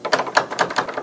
/tfcdownloads/sound/max/rp/doors/
locked.wav